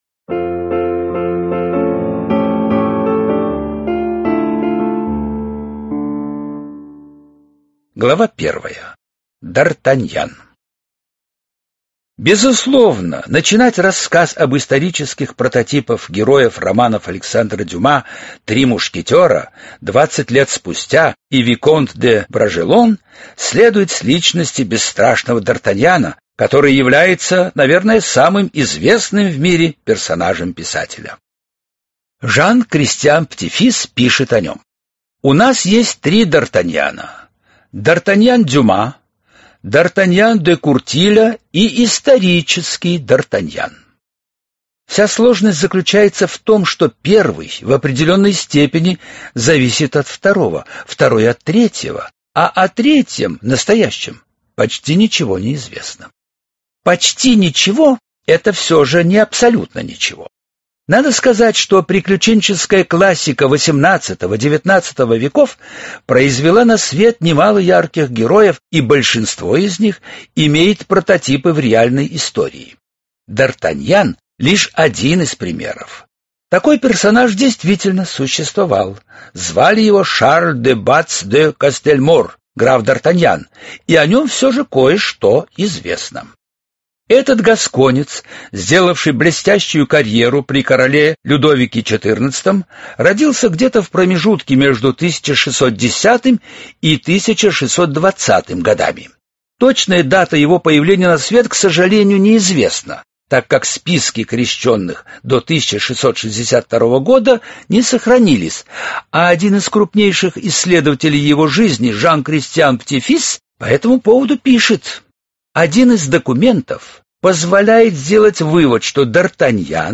Аудиокнига Миледи и три д'Артаньяна | Библиотека аудиокниг